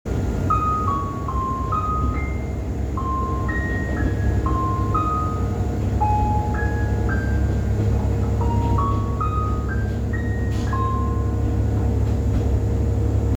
〜車両の音〜
車内チャイム
旧来のディーゼルカーにありがちな、八幡電機産業のオルゴールの「アルプスの牧場」が搭載されているようです。これは撮影会の送迎列車で流れたもので、普段から使用しているのかは分かりません。
Alps.mp3